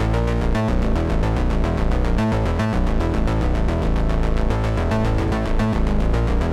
Index of /musicradar/dystopian-drone-samples/Droney Arps/110bpm
DD_DroneyArp1_110-A.wav